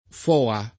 vai all'elenco alfabetico delle voci ingrandisci il carattere 100% rimpicciolisci il carattere stampa invia tramite posta elettronica codividi su Facebook FOA [ f 0 a ] n. pr. f. — sigla di Foreign Operations Administration (ingl.)